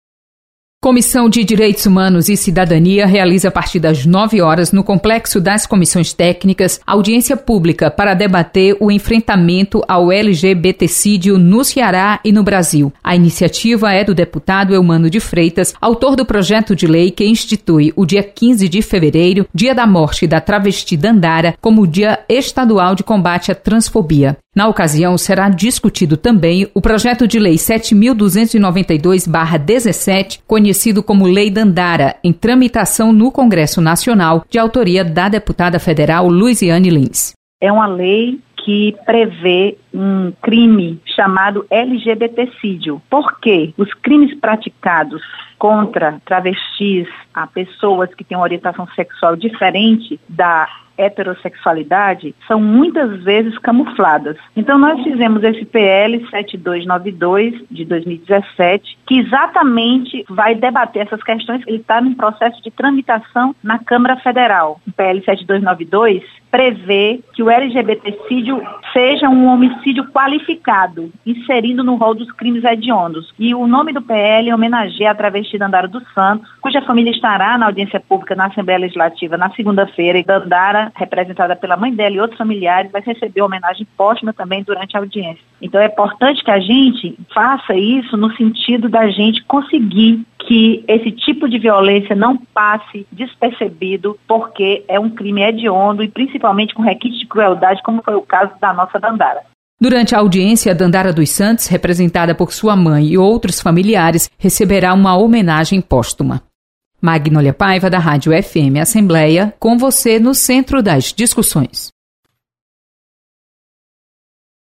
Lei Dandara será debatida na Assembleia Legislativa. Repórter